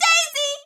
Daisy voice clip from MK8